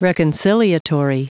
Prononciation du mot reconciliatory en anglais (fichier audio)
Prononciation du mot : reconciliatory